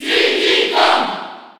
Category:Crowd cheers (SSB4) You cannot overwrite this file.
Diddy_Kong_Cheer_French_PAL_SSB4.ogg